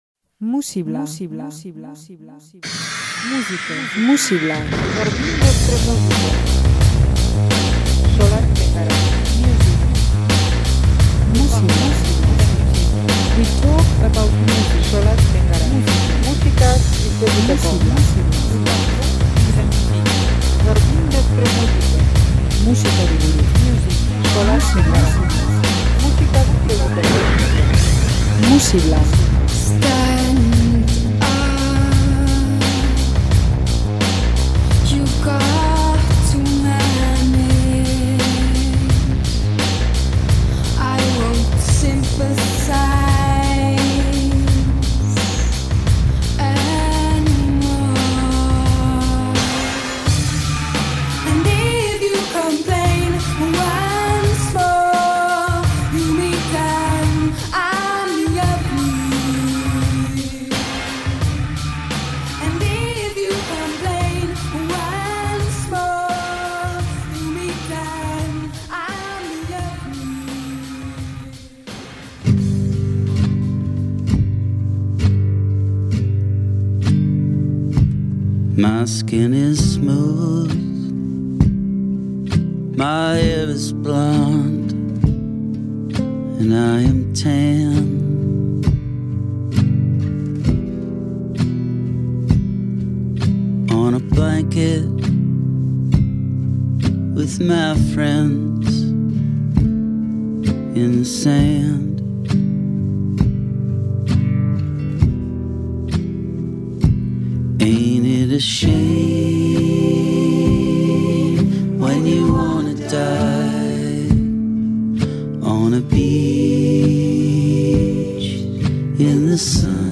erritmo eta melodia indartsuekin